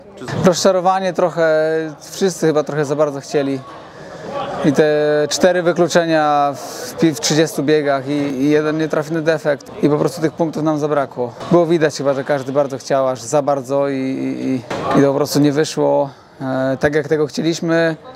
Wypowiedzi po finale: